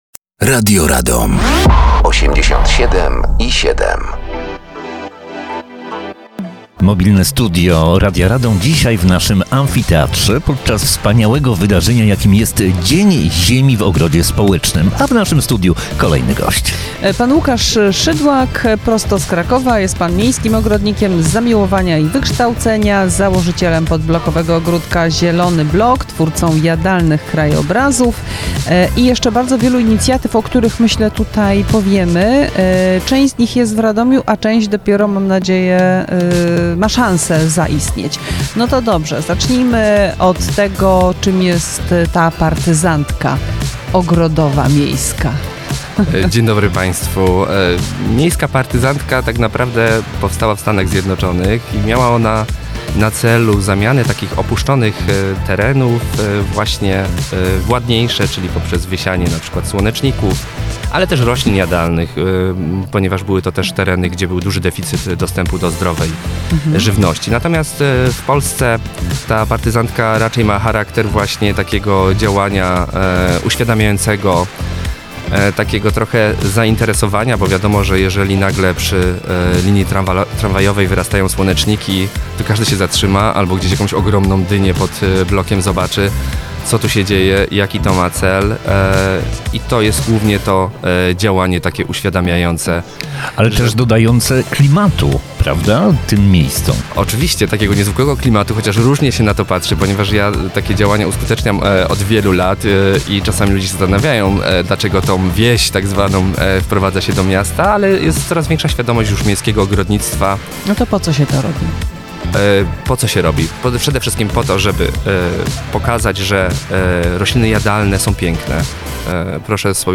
Dziś Dzień Ziemi w Ogrodzie Społecznym radomskiego Amfiteatru